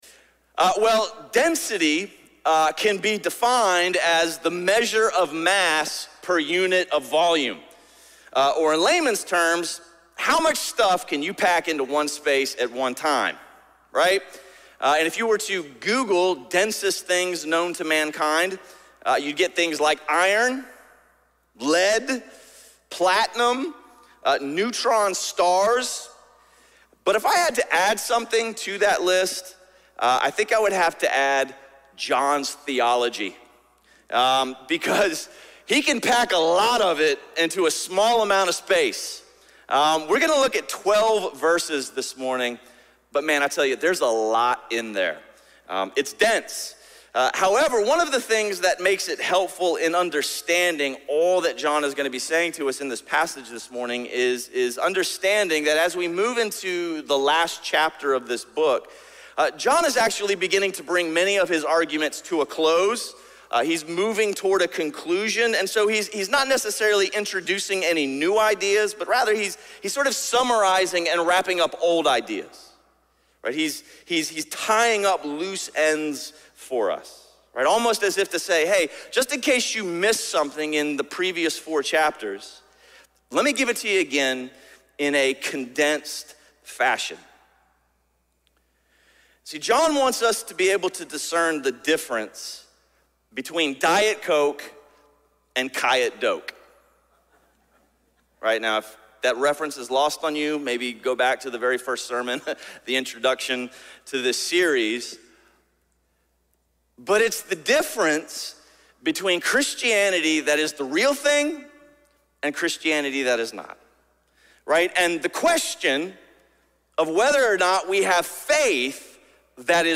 A message from the series "Faith Works."